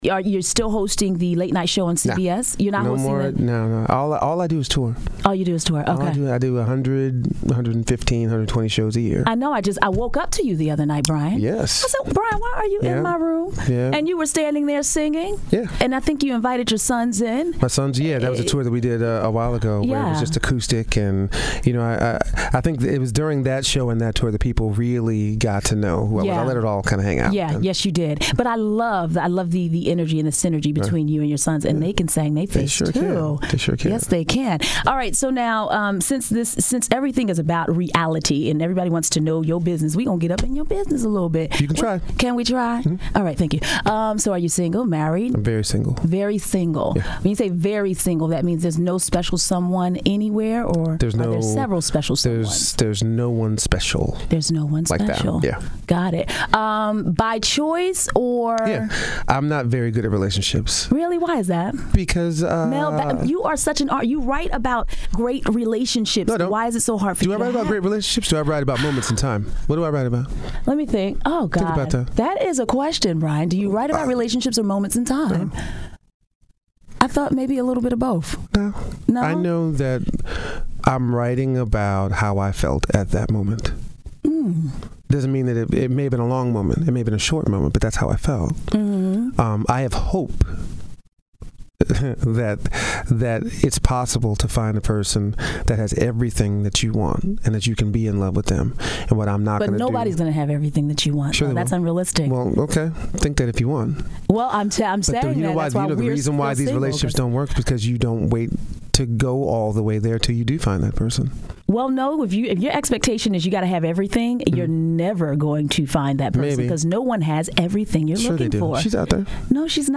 [Audio] An Intimate, Candid Conversation With Brian McKnight